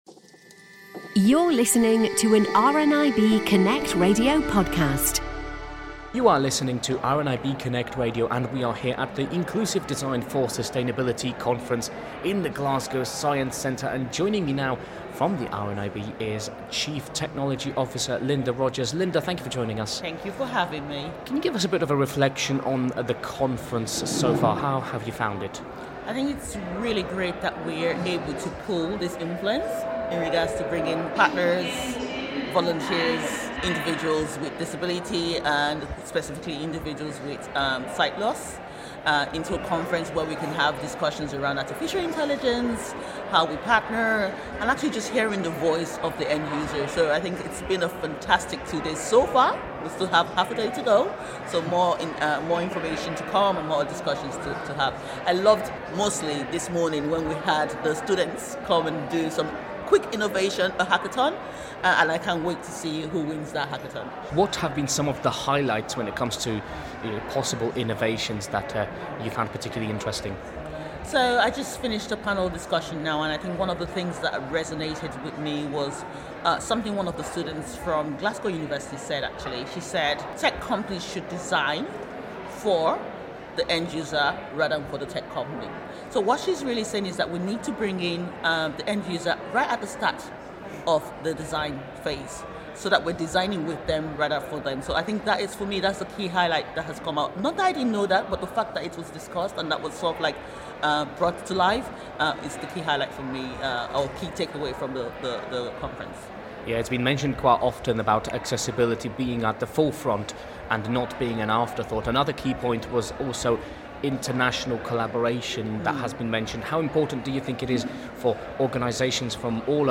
We return to Glasgow now and the inclusive Design For Sustainability Conference